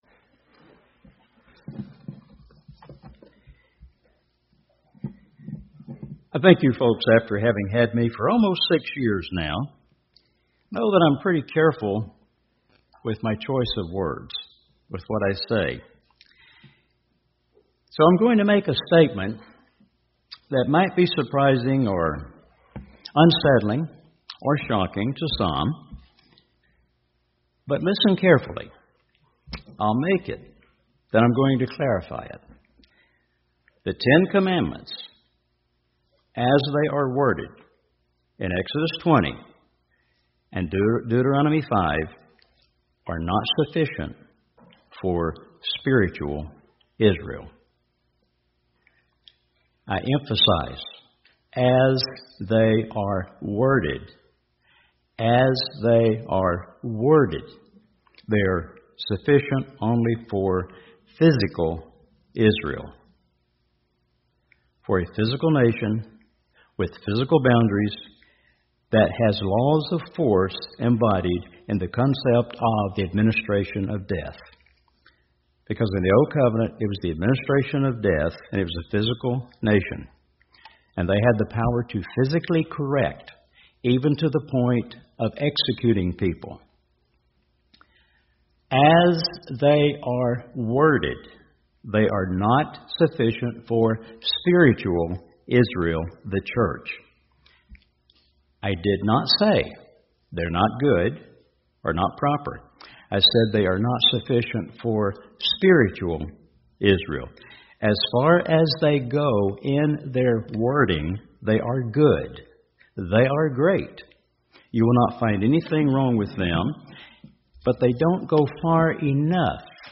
You don't want to miss a single word of this great sermon.